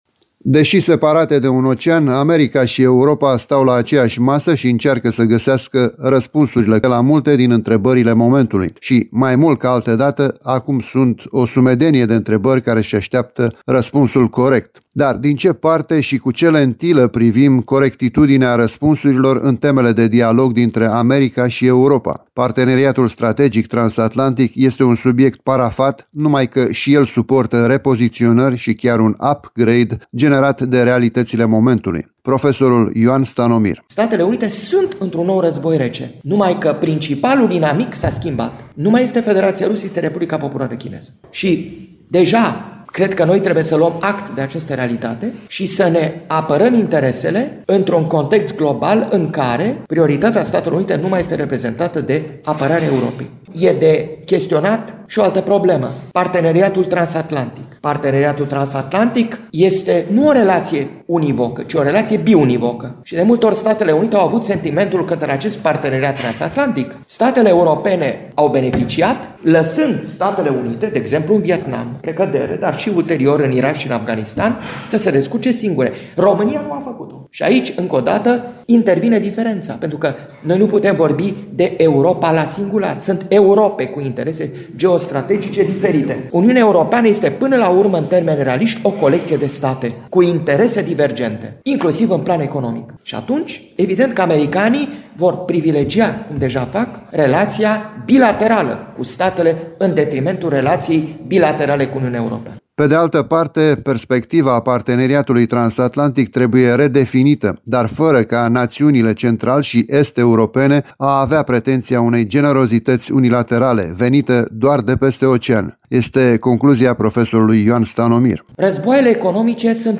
În contextul unei agende internaționale încărcate de subiecte sensibile, dominate de actualizarea parteneriatului transatlantic, Facultatea de Istorie și Științe Politice din cadrul Universității Ovidius din Constanța a organizat, la sfârșitul săptămânii trecute, dezbaterea intitulată „Europa și America – o relație complicată”.